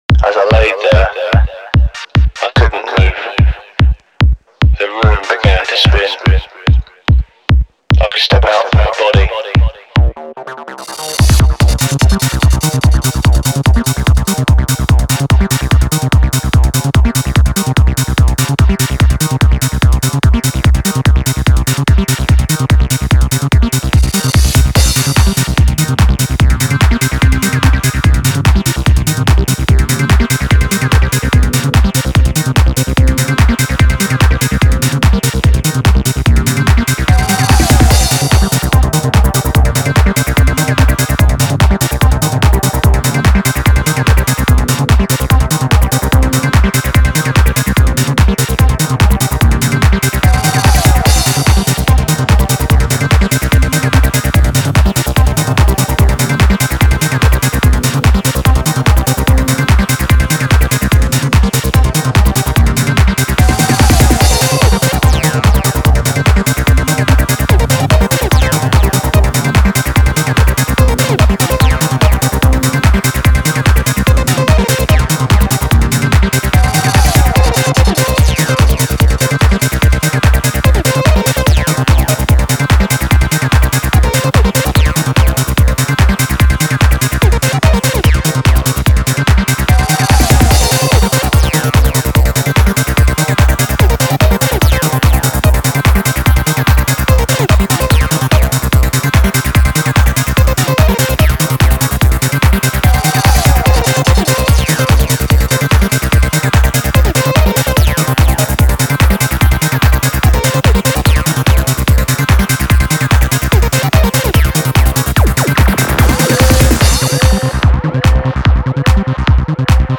Файл в обменнике2 Myзыкa->Psy-trance, Full-on
Style: Goa Trance
Quality: 320 kbps / 44,100 Hz / Full Stereo